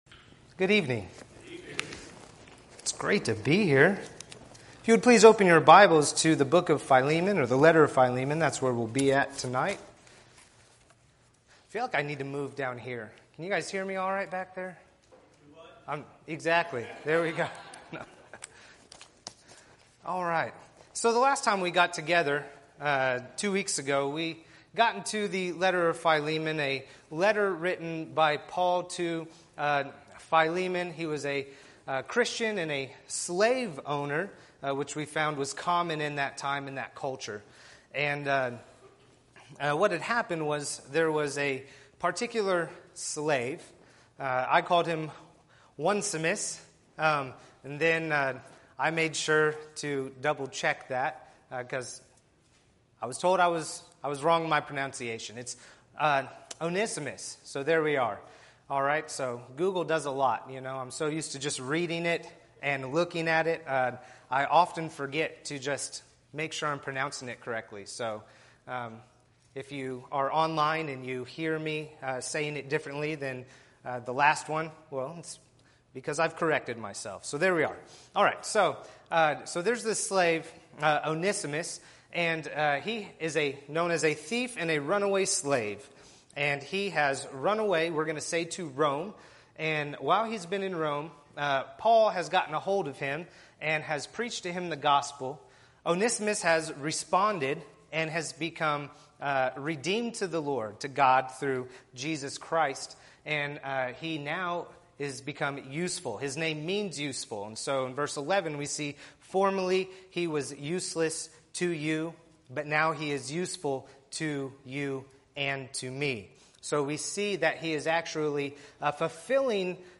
Podcasts Videos Series Sermons Philemon